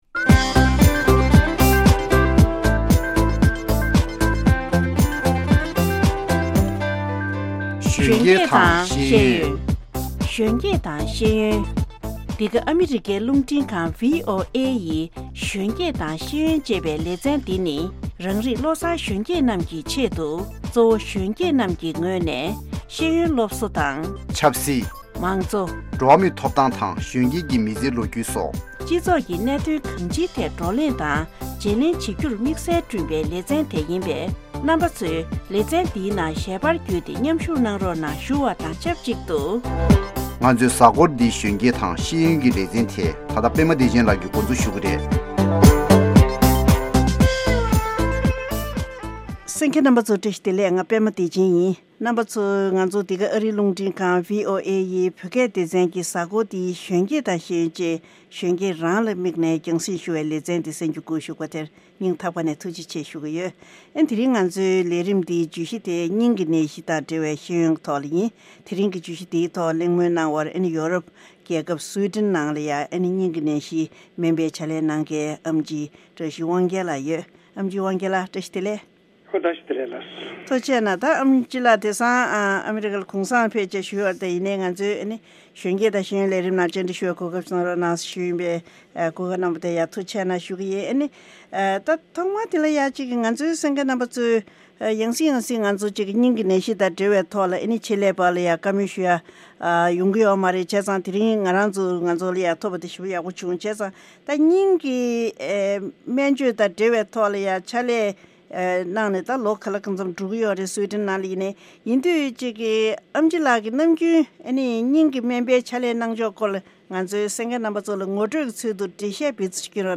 Interview with Cardiologist